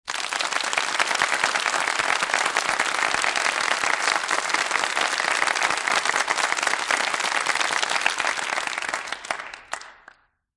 small_studio_audience_polite_applause